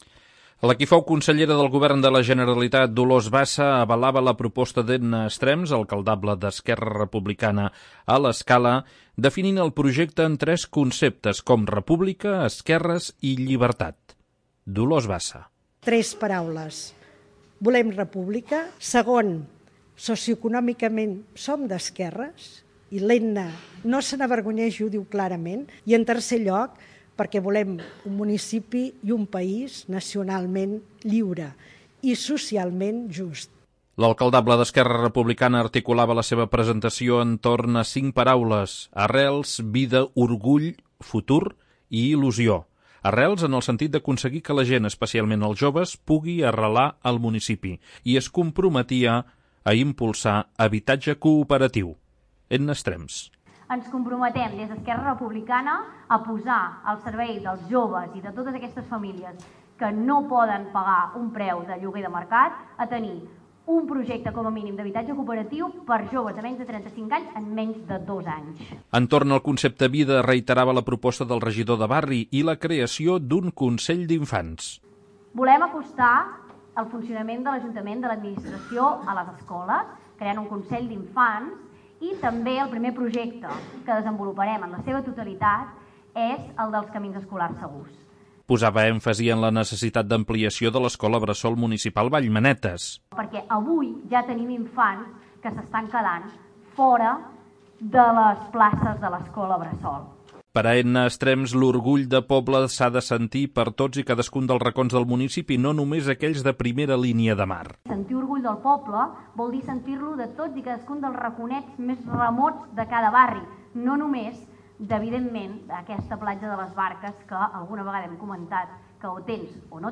Mig centenar d'assistents omplien l'aforament de la sala petita del Centre Cultural Xavier Vilanova.